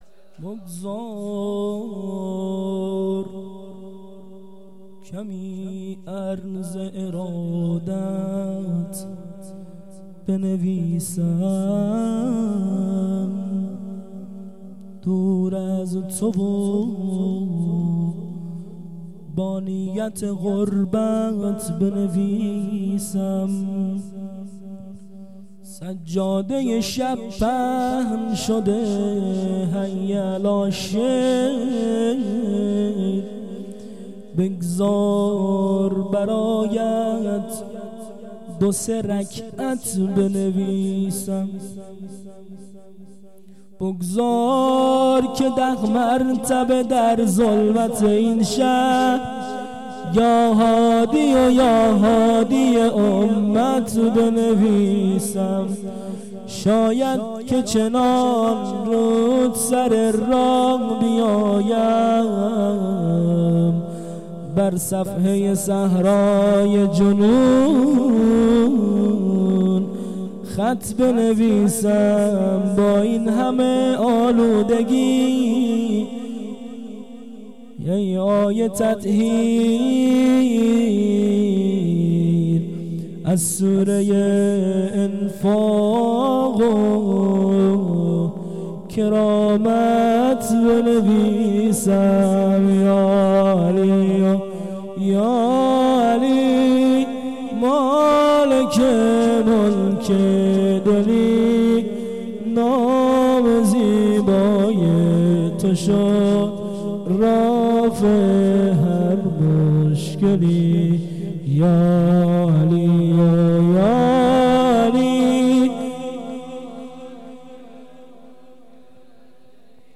مدح
شهادت امام هادی(ع)|۲۸بهمن۱۳۹۹
مدح خوانی